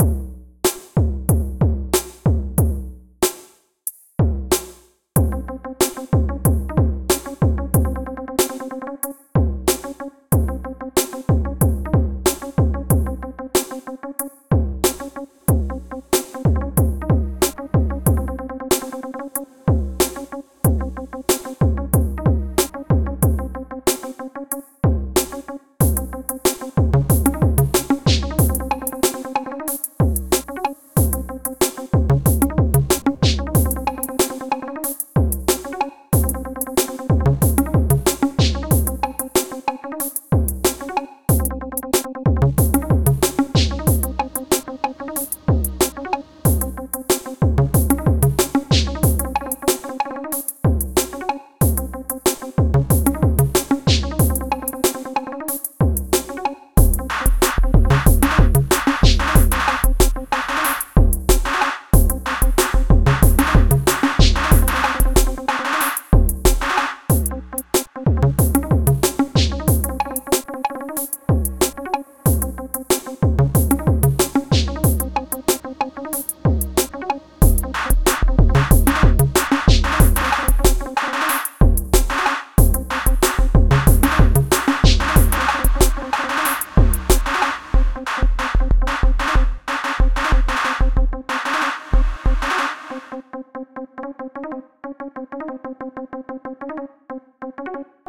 Pieza Intelligent dance music (IDM)
Música electrónica
melodía
sintetizador